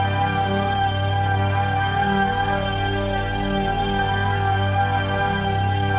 Index of /musicradar/dystopian-drone-samples/Non Tempo Loops
DD_LoopDrone2-F.wav